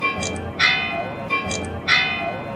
3) ¡Escucha! Este es el sonido de campanas de un cuarto; cuando sean "y media", sonarán dos cuartos ; y para "menos cuarto" sonará tres veces.